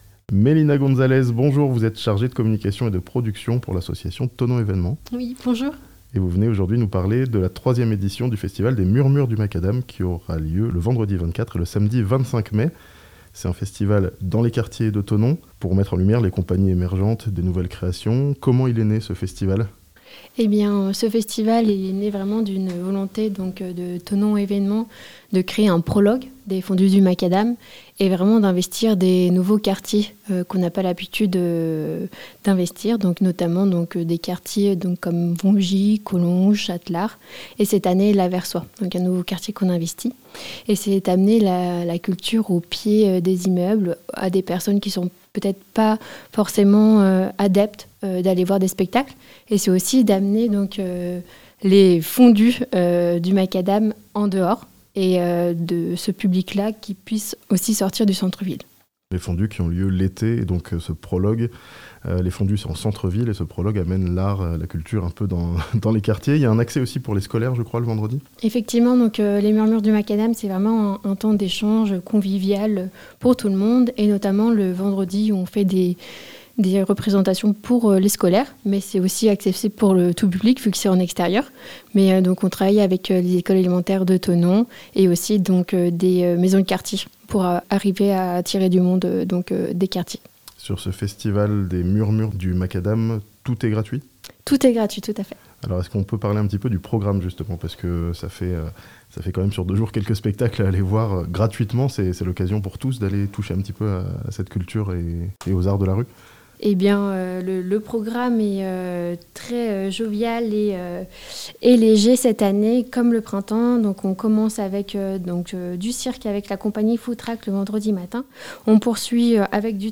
Le festival des Murmures du Macadam investira les quartiers de Thonon les 24 et 25 mai (interview)